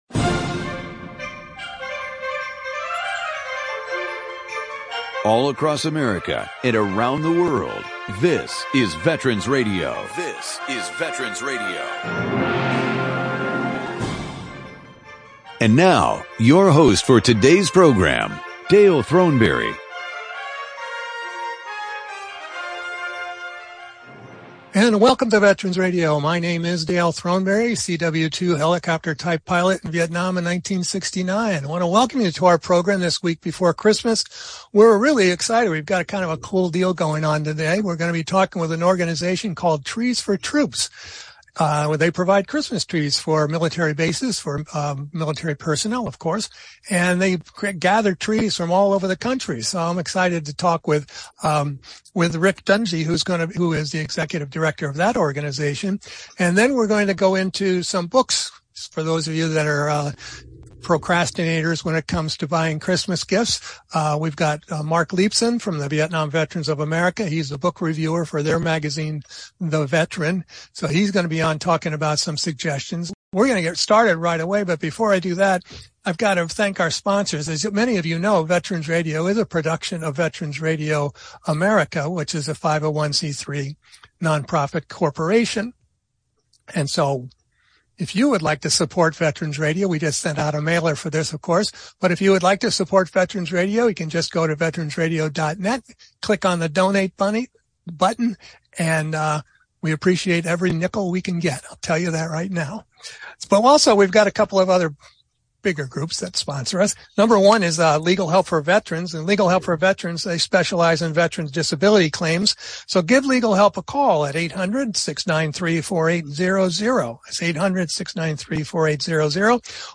Trees for Troops + Book Reviews